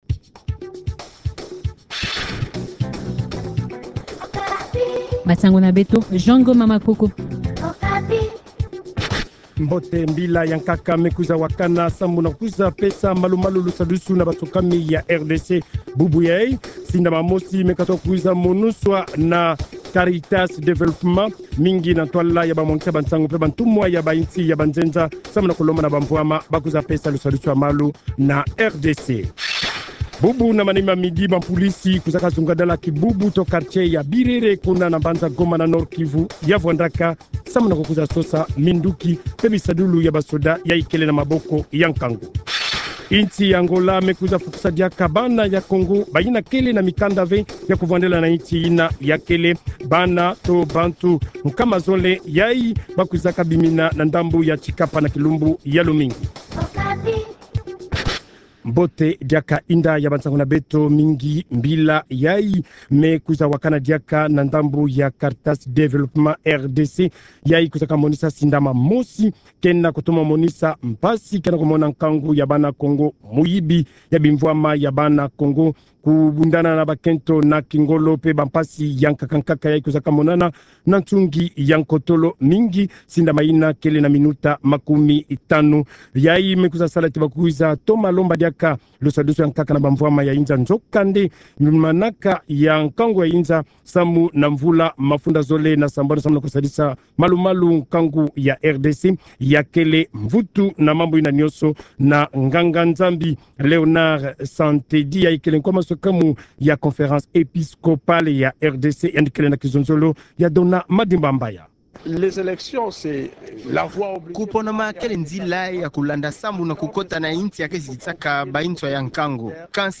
Journal Kikongo